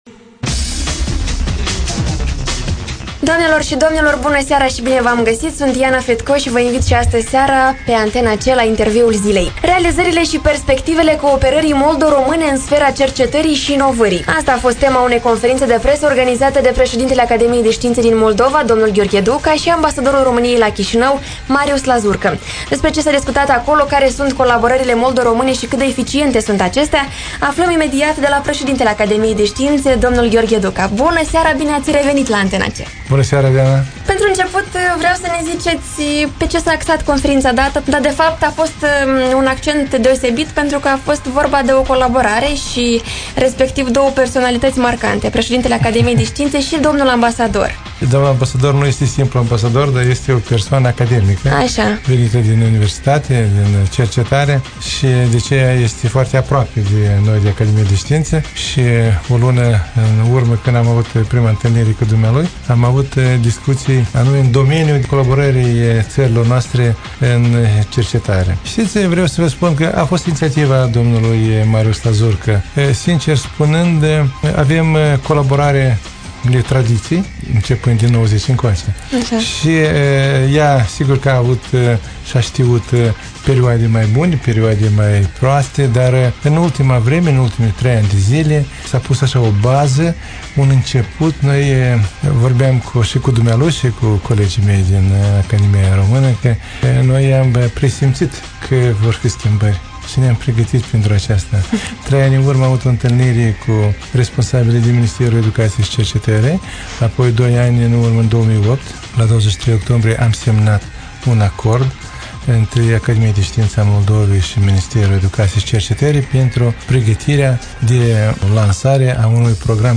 Antena C: Interviul zilei cu Gheorghe Duca
Asculta Interviul zilei cu Gheorghe Duca, realizat de radio Antena C